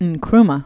(ən-krmə, əng-), Kwame Originally Francis Nwia Kofi. 1909-1972.